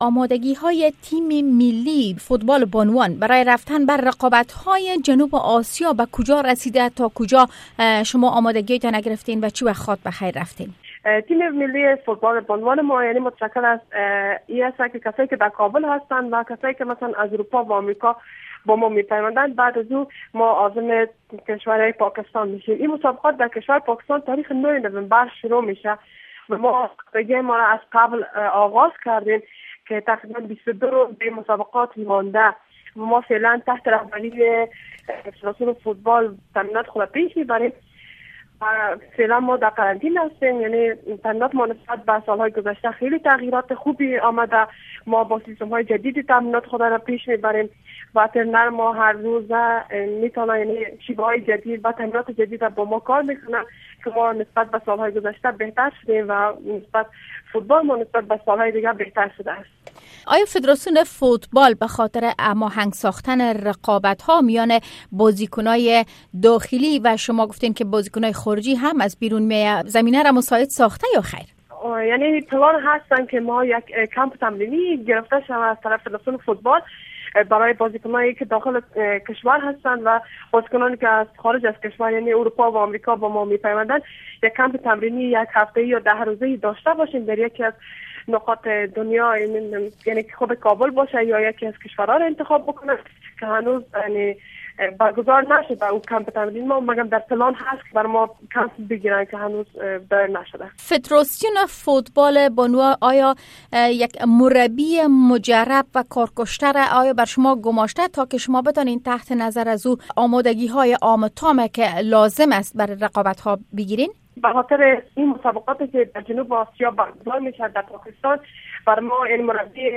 مصاحبۀ